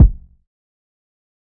TC Kick 16.wav